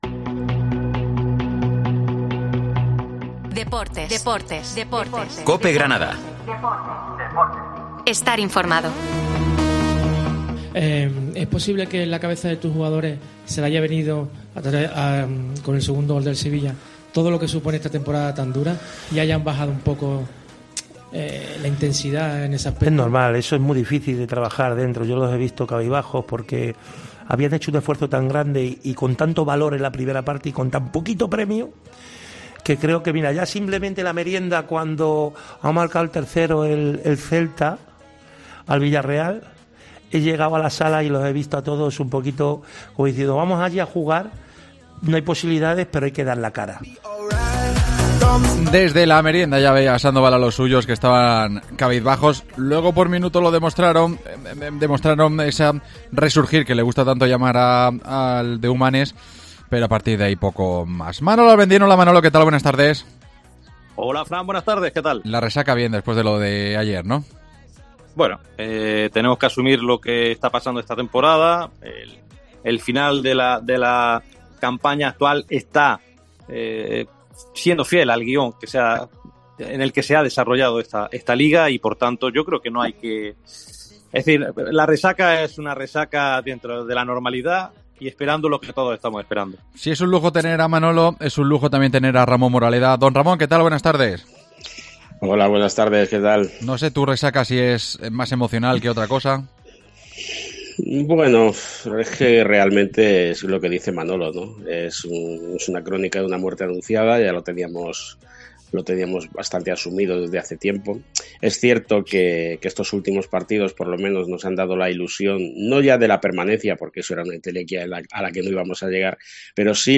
AUDIO: La derrota del conjunto rojiblanco en Sevilla y la visita del Real Madrid centran los temas de nuestra tertulia de los lunes